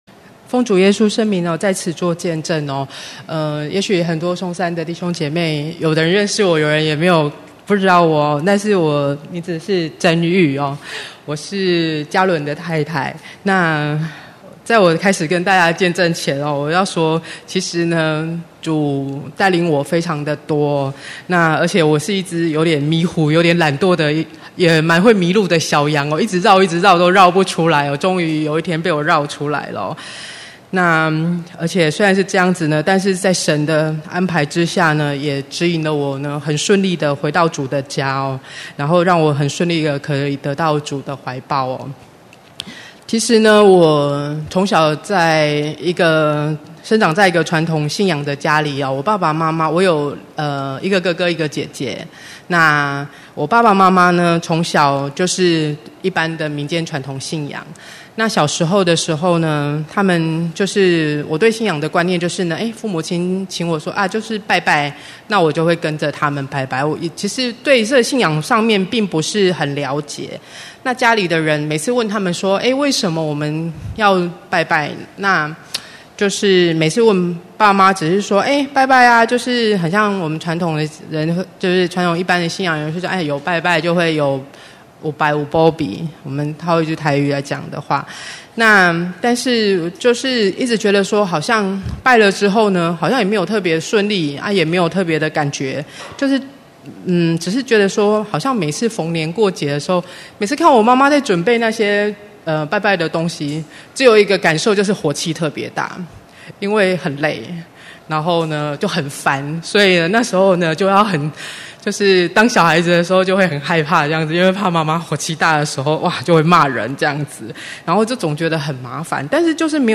2017年9月份講道錄音已全部上線